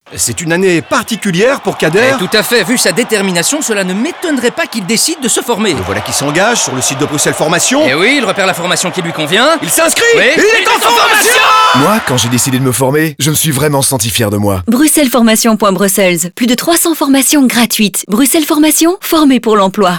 BF_spot_radio.wav